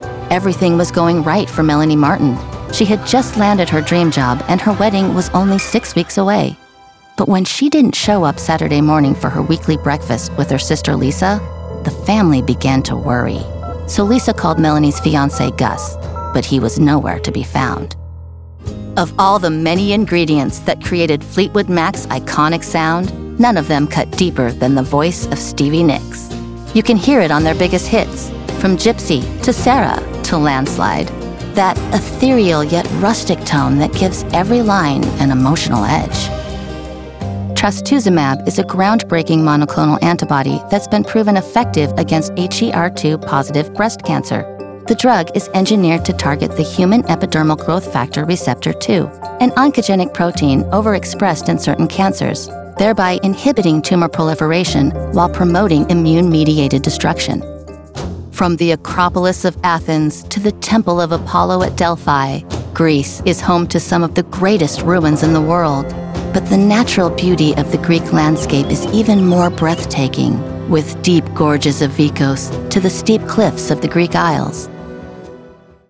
I have a warm, conversational style - a real girl next door
Narrative Demo 2024